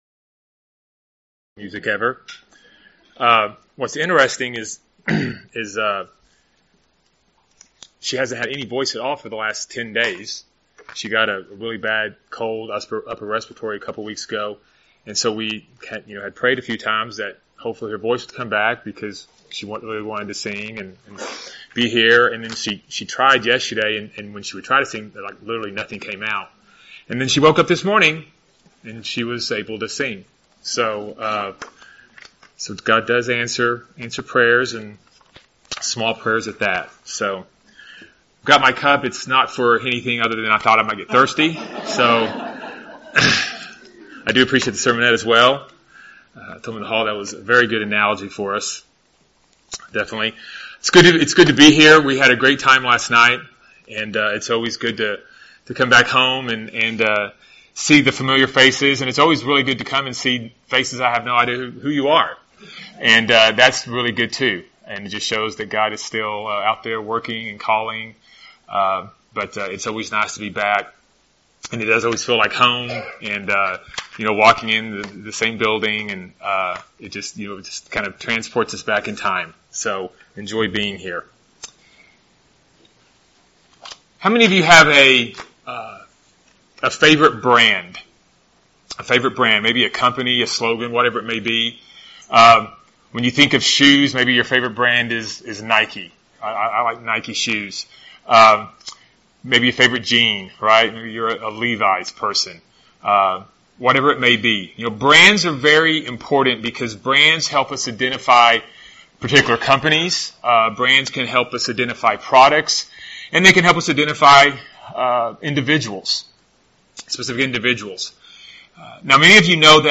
Sermons
Given in Lubbock, TX